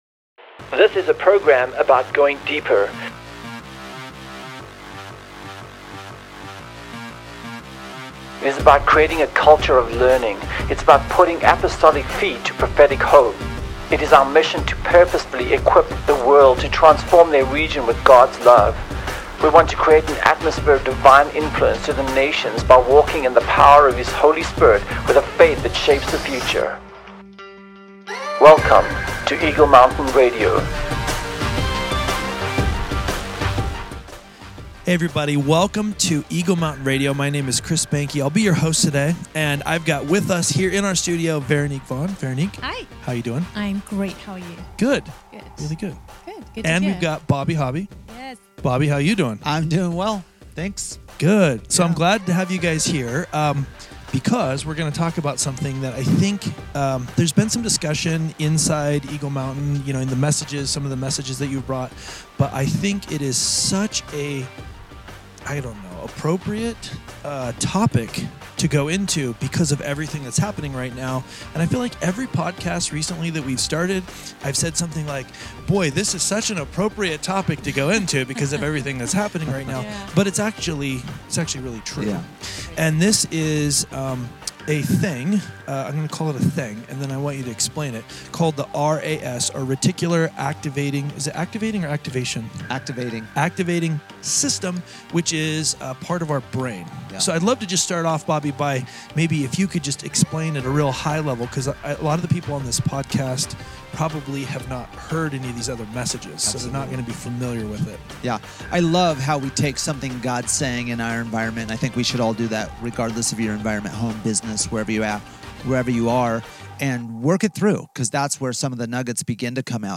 Our panel discusses how we can overcome these traumatic events, heal, and train our RAS to seek out the truth that will set us free.